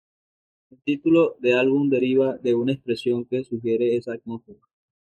at‧mós‧fe‧ra
Pronounced as (IPA)
/adˈmosfeɾa/